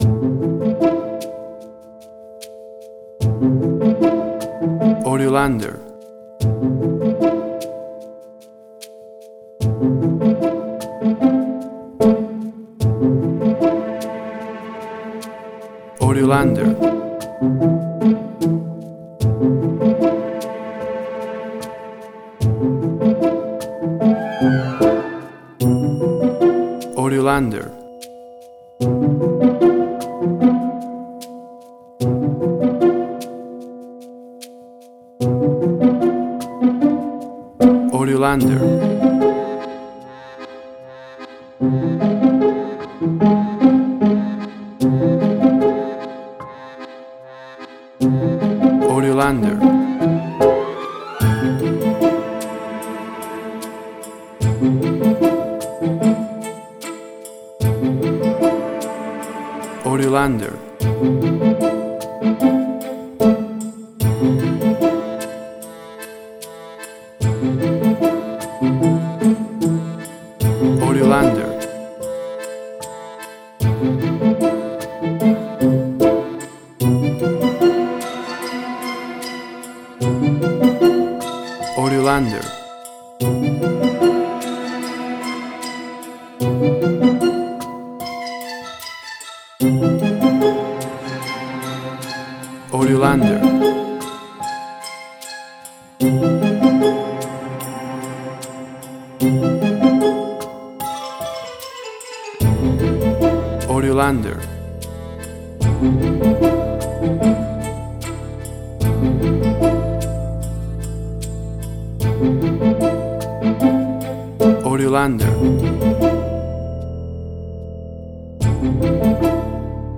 Suspense, Drama, Quirky, Emotional.
Mystery, investigation, crime, suspense
Tempo (BPM): 87